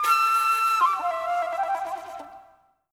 01_Flute_1.wav